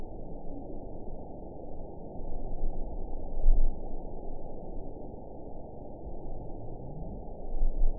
event 913827 date 04/21/22 time 22:40:17 GMT (3 years, 1 month ago) score 8.98 location TSS-AB03 detected by nrw target species NRW annotations +NRW Spectrogram: Frequency (kHz) vs. Time (s) audio not available .wav